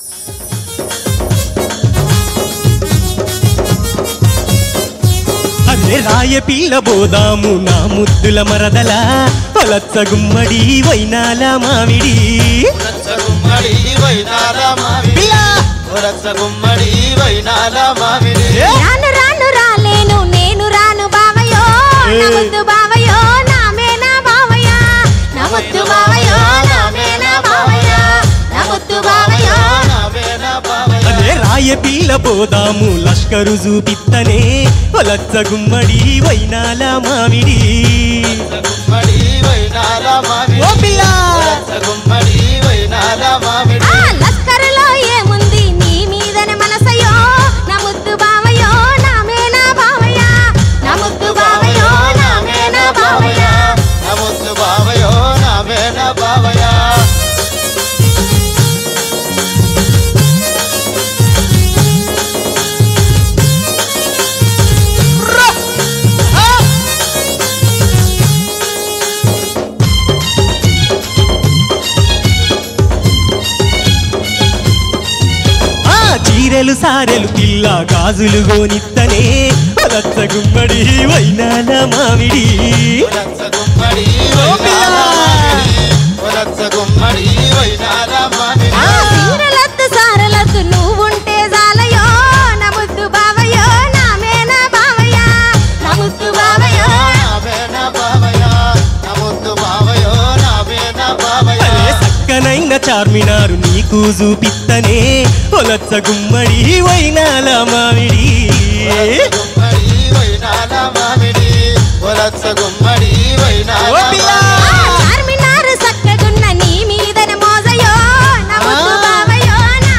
CategoryTelangana Folk Songs